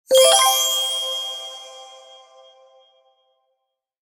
Success Chime Sound Effect
Bright and uplifting success chime, perfect for UI notifications, apps, games, and digital projects. This bright, cheerful sound instantly conveys achievement, accomplishment, and positive feedback.
Success-chime-sound-effect.mp3